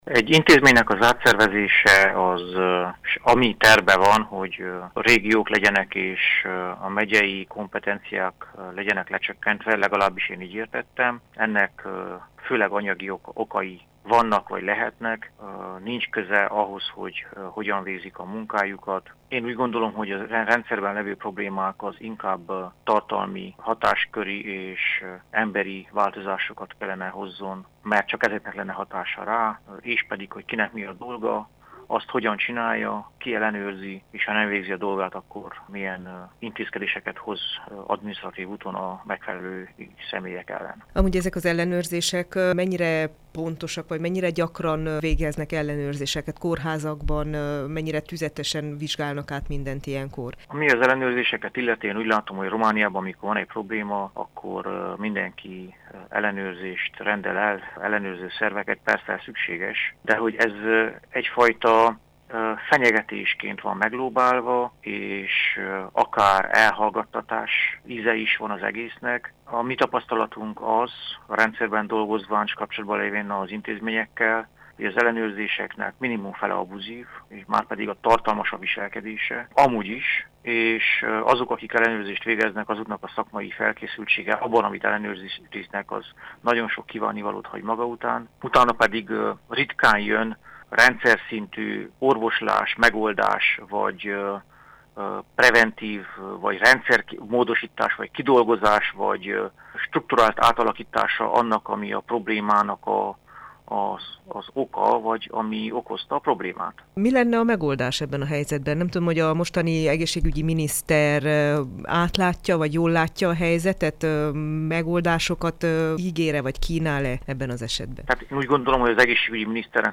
egészségügyi közgazdászt hallják